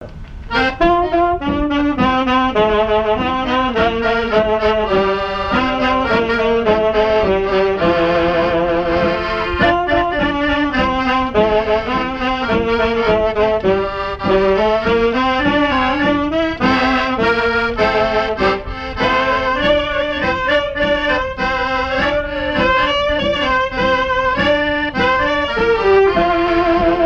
Vie de l'orchestre et son répertoire, danses des années 1950
Pièce musicale inédite